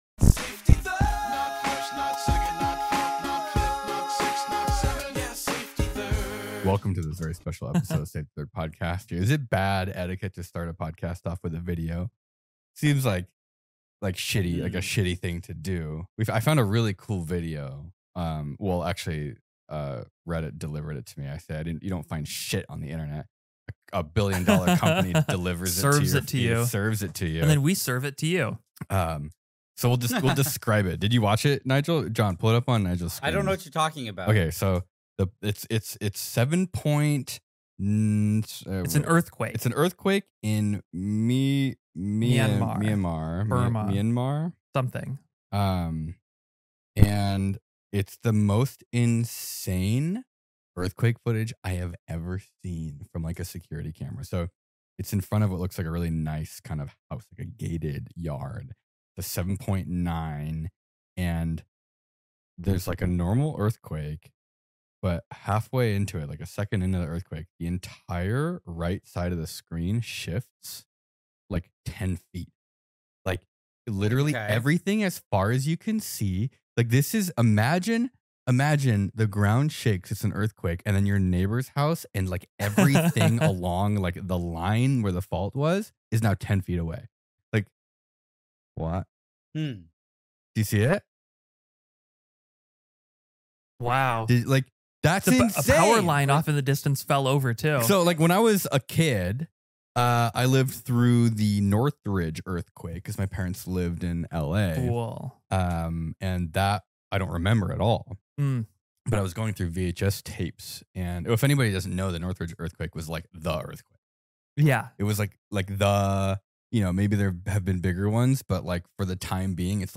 Safety Third is a weekly show hosted by William Osman, NileRed, The Backyard Scientist, Allen Pan and a couple other YouTube "Scientists".